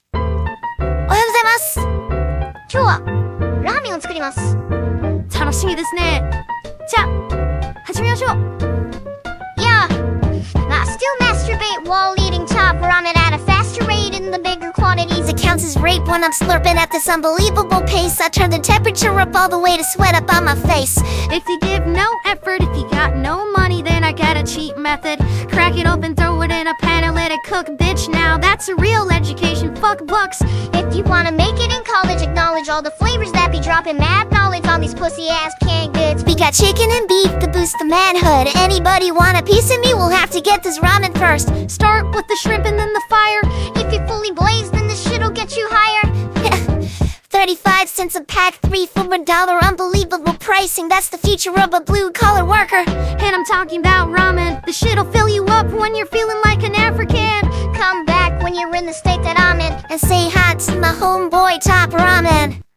Been experimenting with this and audacity.